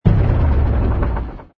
engine_pi_fighter_kill.wav